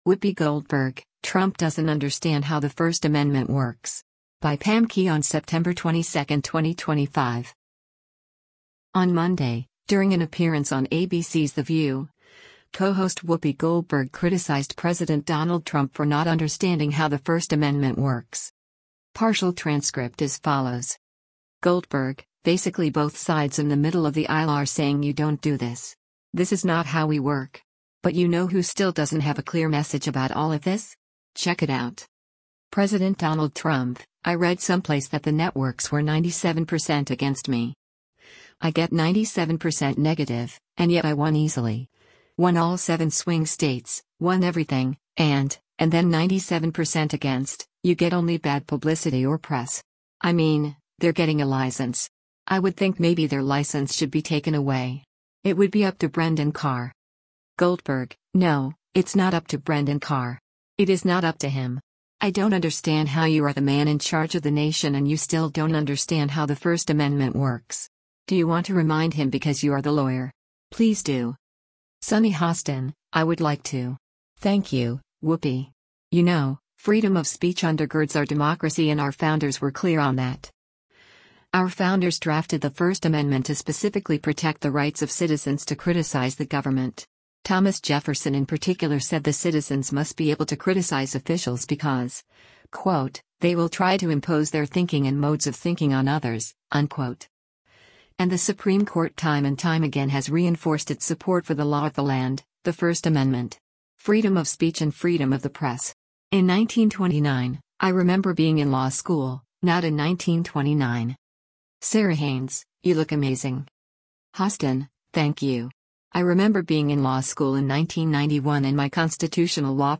On Monday, during an appearance on ABC’s “The View,” co-host Whoopi Goldberg criticized President Donald Trump for not understanding how the First Amendment works.